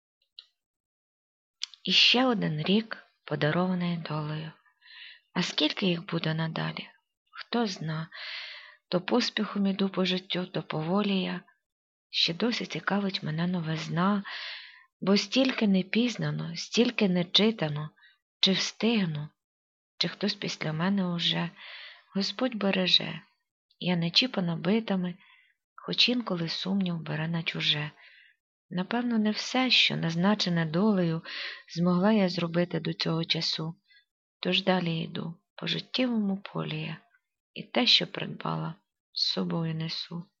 Рубрика: Поезія, Поетична мініатюра
Ы так цыкаво почути живе виконання!